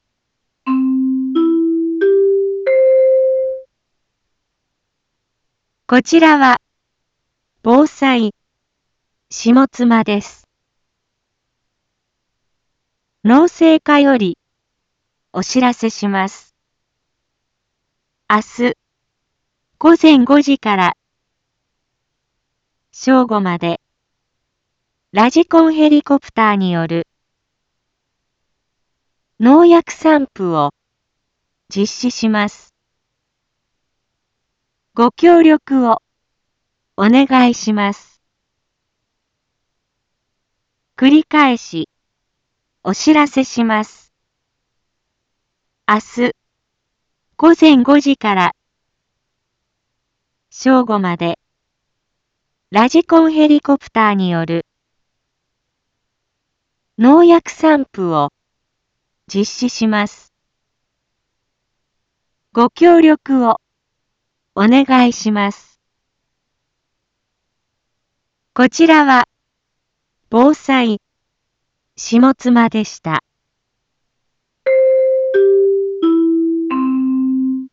一般放送情報
Back Home 一般放送情報 音声放送 再生 一般放送情報 登録日時：2021-09-09 12:31:22 タイトル：ﾗｼﾞｺﾝﾍﾘによる防除（豊加美、高道祖） インフォメーション：こちらは防災下妻です。